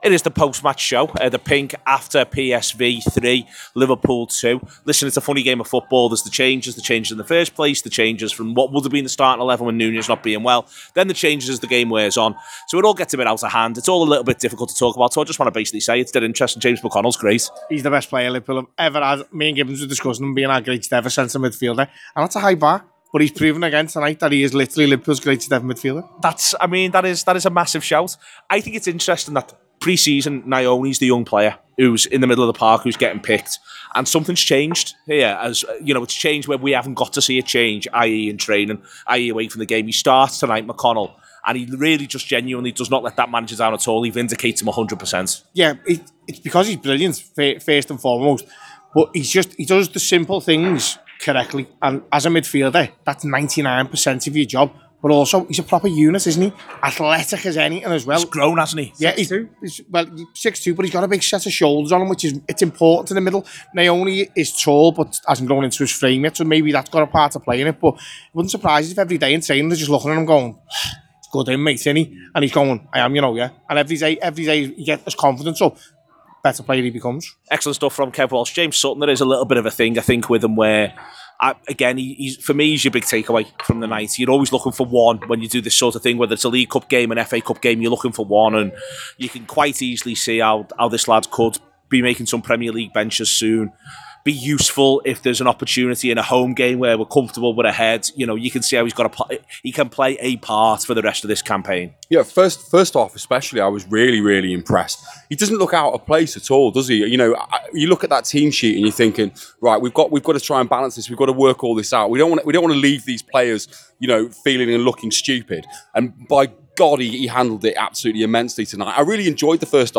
The Anfield Wrap’s post-match reaction podcast live from Gravity Max in Liverpool One after PSV 3 Liverpool 2 in the last 2024-2025 Champions League first stage game.